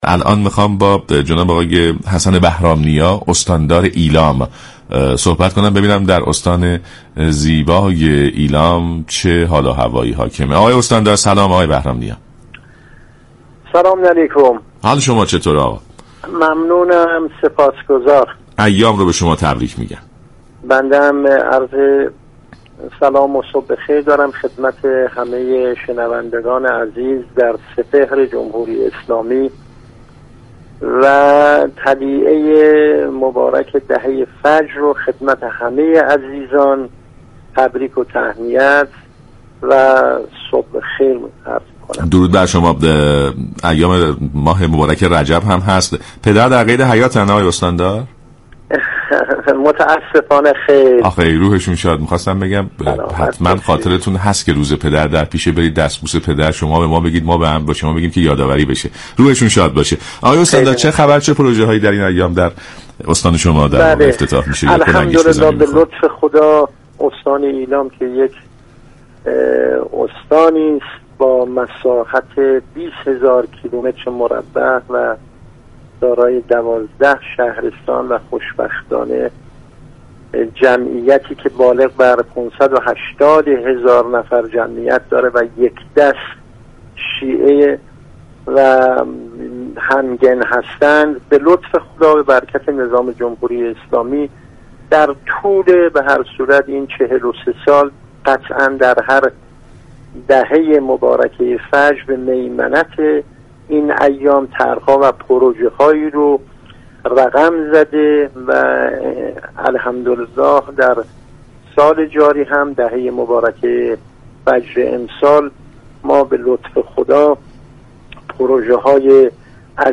به گزارش شبكه رادیویی ایران، حسن بهرام نیا استاندار ایلام در برنامه «صبح انقلاب» درباره طرح های افتتاحی در استان گفت: استان ایلام در دهه مبارك فجر طرح و پروژه های خوبی را در دستور كار خود قرار داده و 243 طرح را با اعتبار 20 هزار و 432 میلیارد ریال افتتاح كرده است.